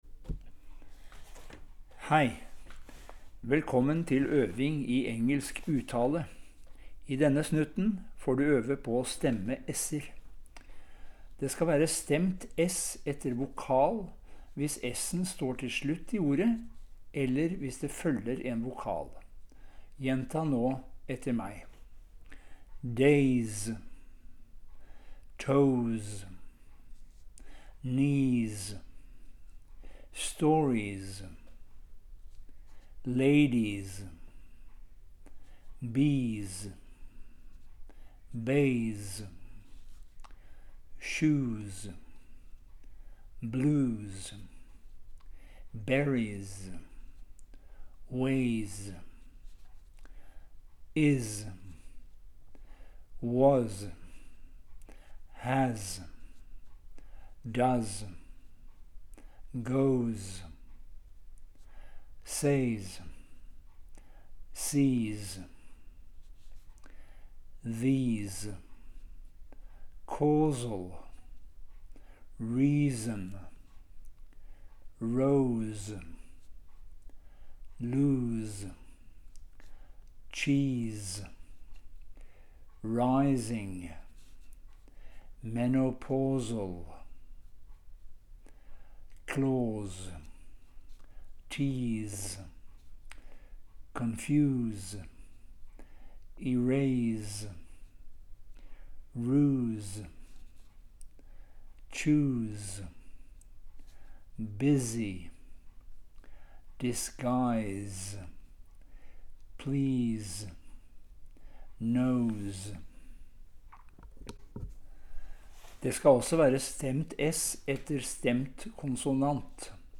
Del 1: Stemte s’er og sj-lyder
Stemt s etter vokal hvis s’en står til slutt i ordet eller det følger en vokal:
stemt s.MP3